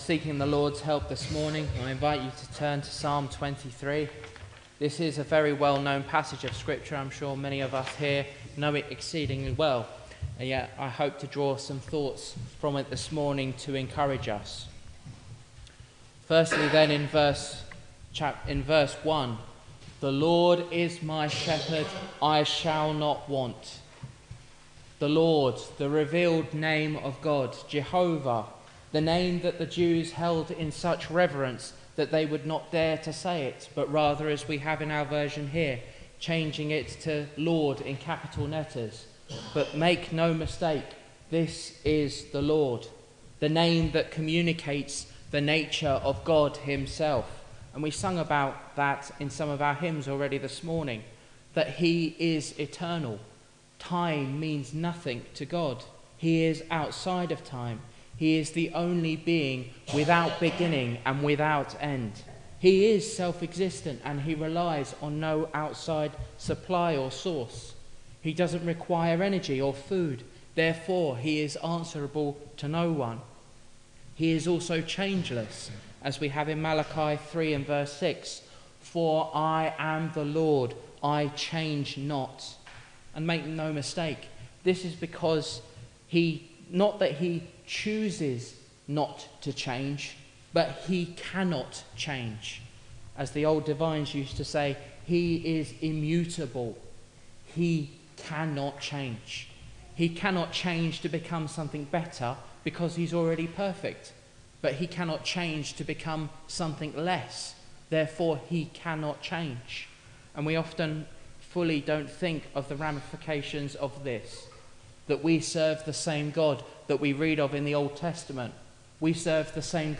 Teaching and Gospel sermons from the Psalms